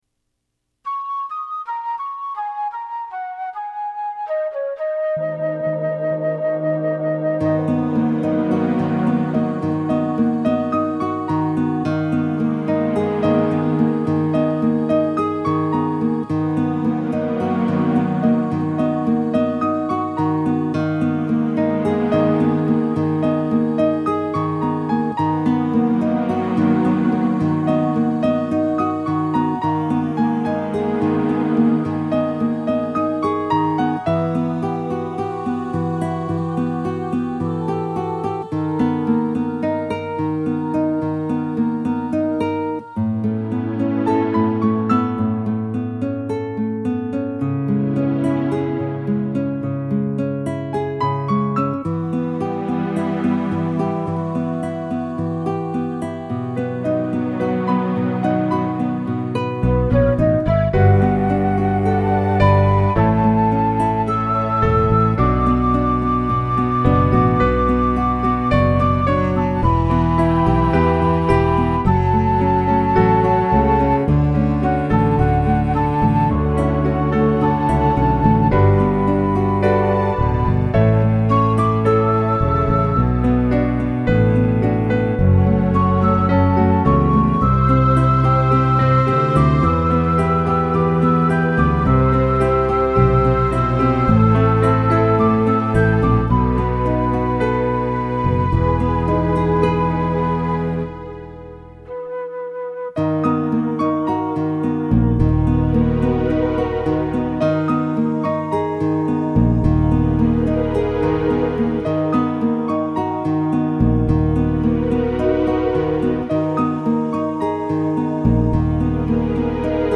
Originally, the music for my song was inspired by a classical guitar instrumental named “Waterfalls.”
TAKE ME AWAY Midi in progress